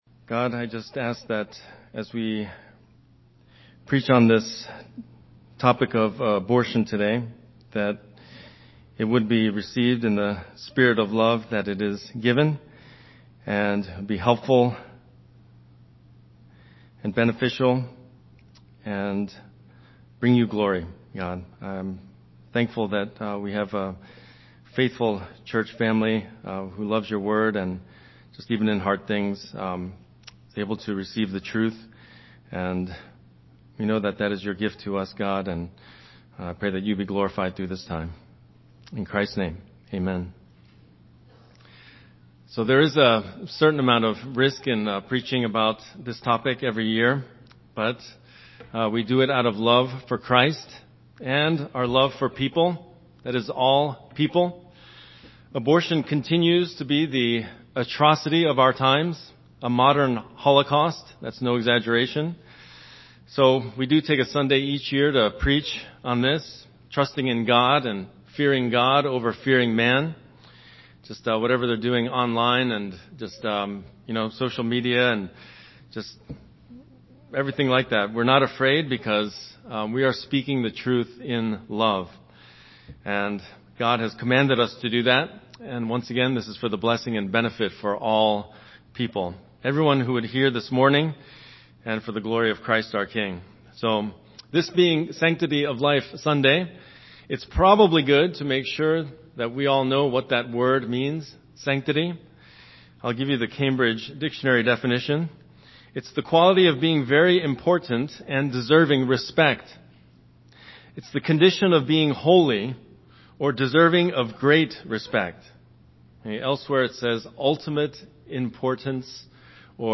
Recent Sermons - Faith Bible Church